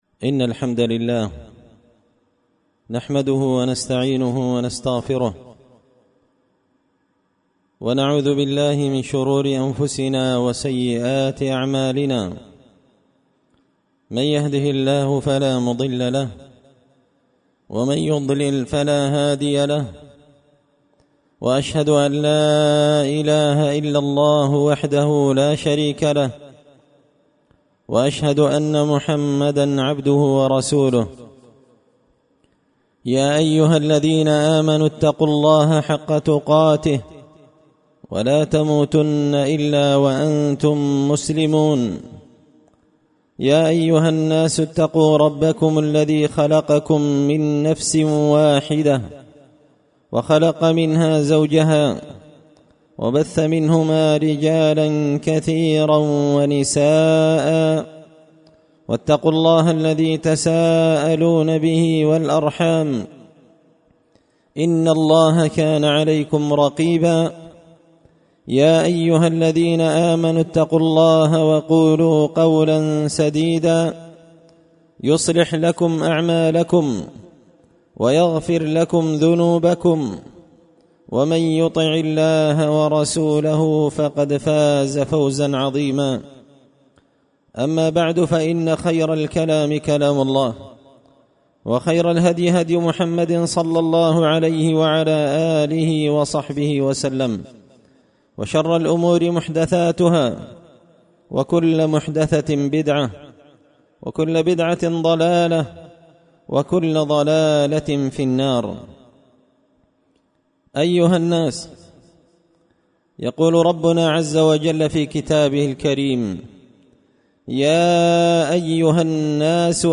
خطبة جمعة بعنوان – فضل لاحول ولاقوة إلا بالله
دار الحديث بمسجد الفرقان ـ قشن ـ المهرة ـ اليمن